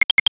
NOTIFICATION_8bit_02_mono.wav